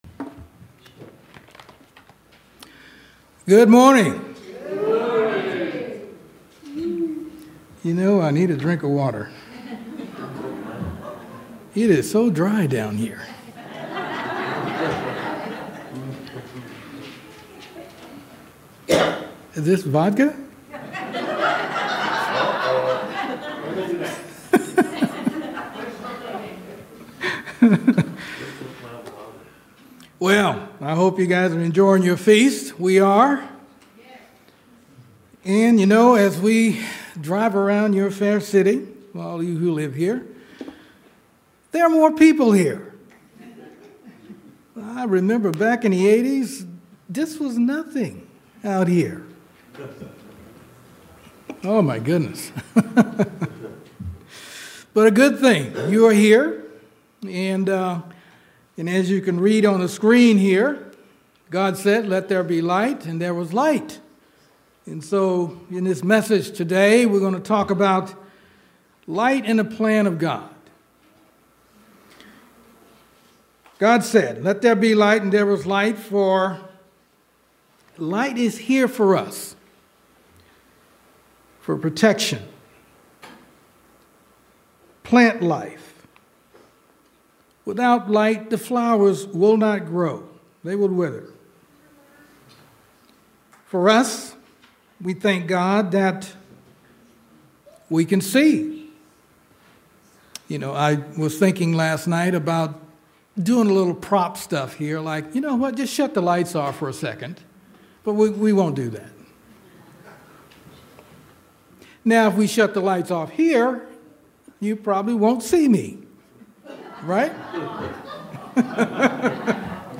This sermon looks at the role that light plays in the plan of God.